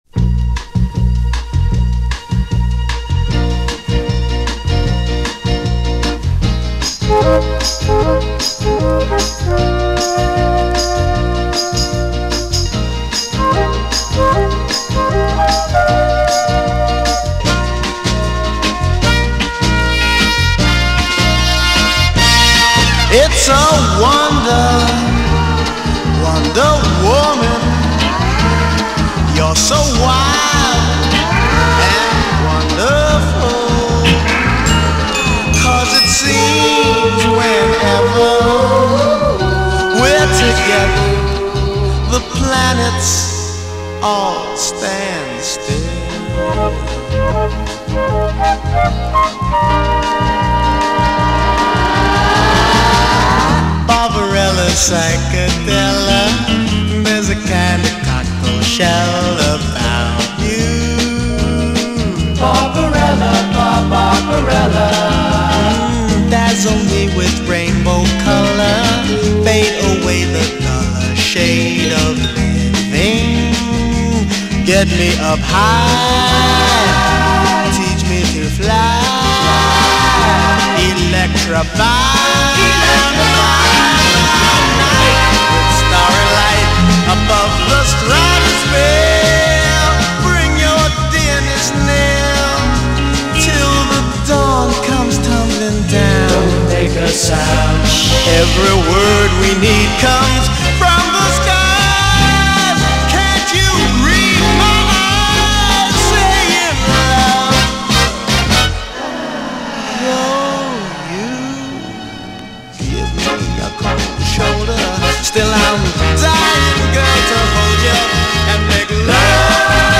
“But Hey” style vocals